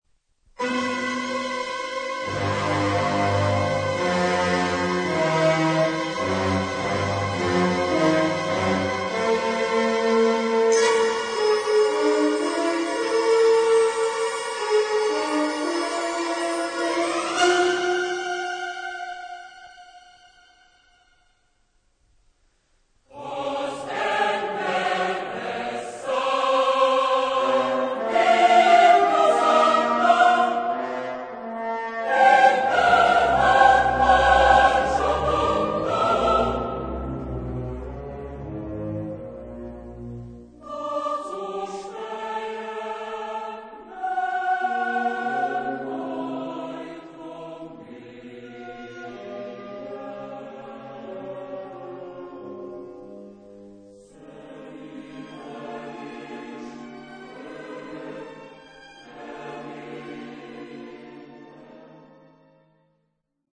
Instrumentation : Orchestre symphonique